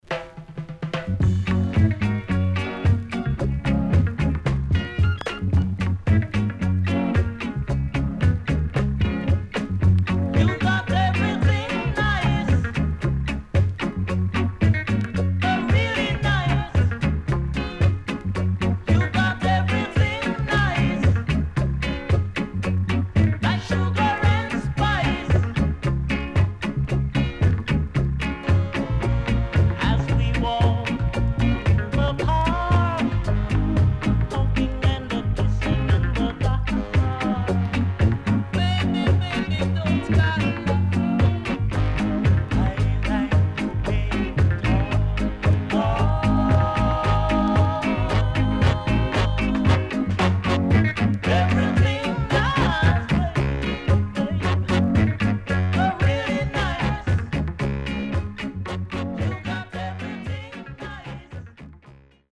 HOME > REGGAE / ROOTS  >  FUNKY REGGAE
SIDE A:うすいこまかい傷ありますがノイズあまり目立ちません。